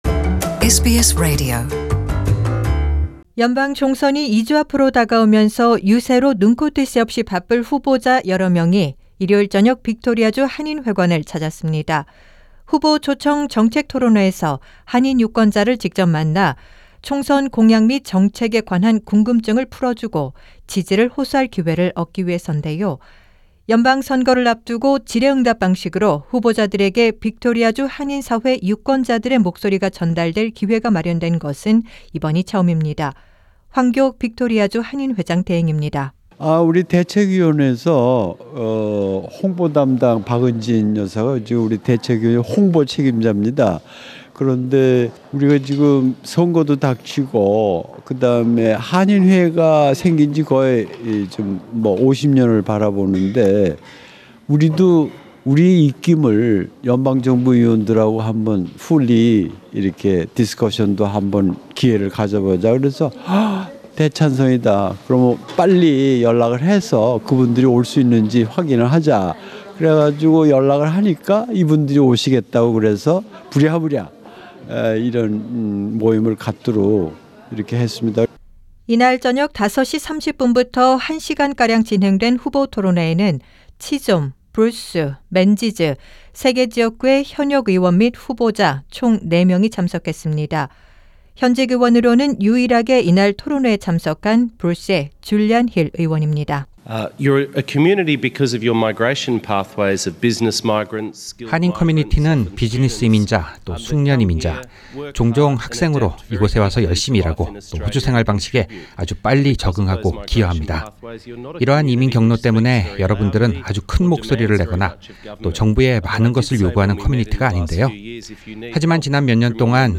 As the Election Day approaches fast, Korean-Australian voters in Victoria had an opportunity to ask questions to and raise concerns with candidates in a policy forum, during which Labor MP Julian Hill declared its migration policy would be ‘non-discriminatory’ and ‘balanced’.